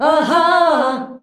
AHAAH F.wav